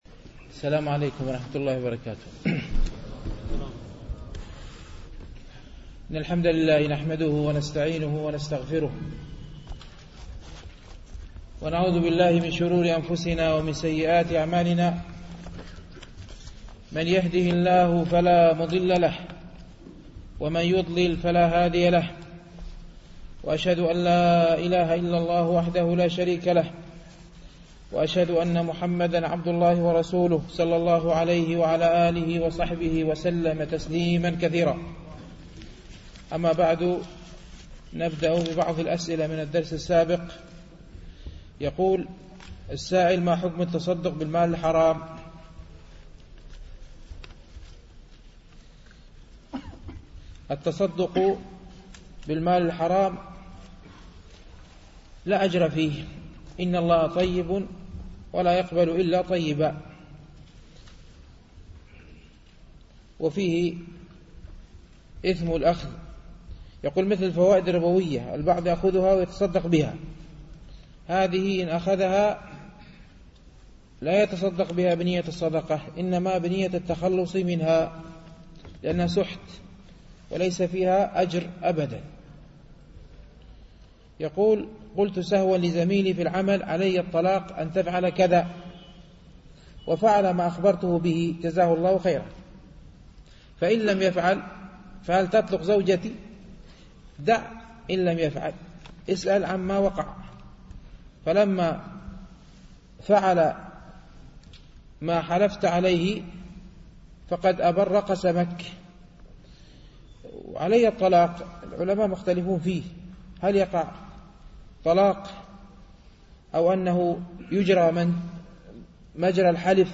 شرح رياض الصالحين - الدرس الرابع والخمسون بعد المئة